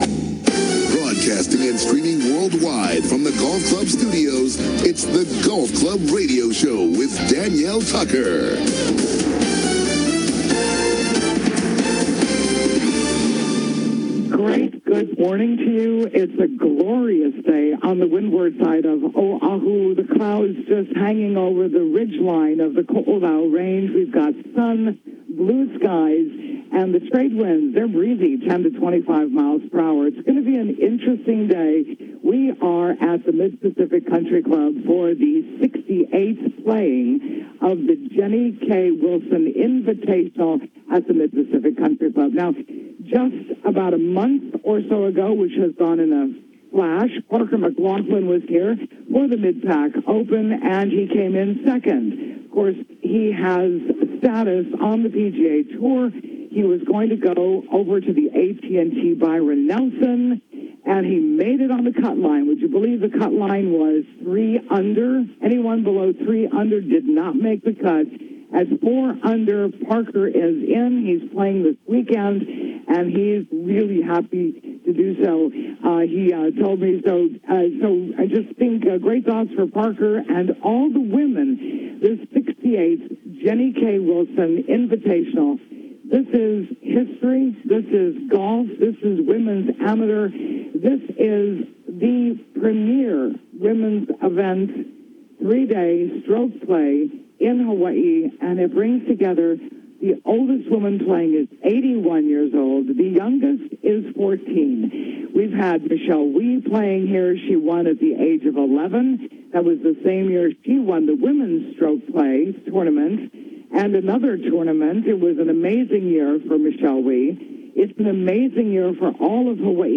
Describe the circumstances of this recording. Live Remote From The Jenny K. Women�s Invitational Mid Pacific Country Club in Lanikai on Oahu